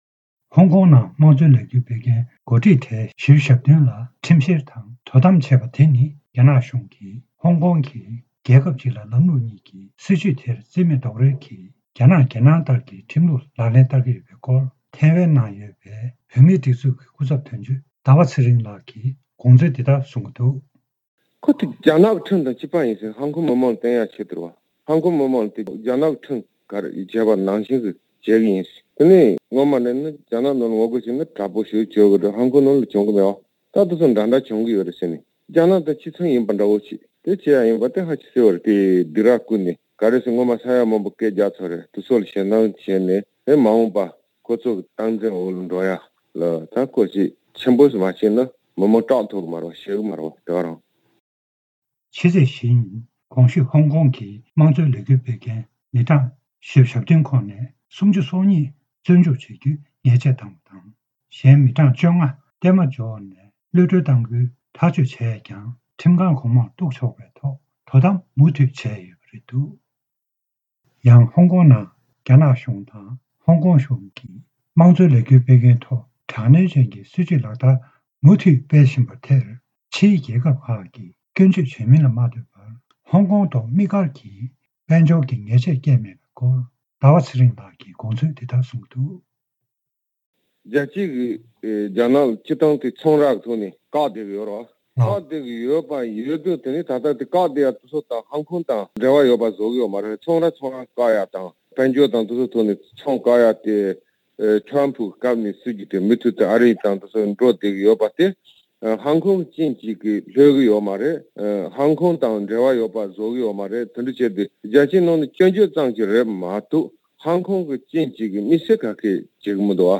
གནས་འདྲི་ཞུས་ནས་ཕྱོགས་སྒྲིག་ཞུས་པ་ཞིག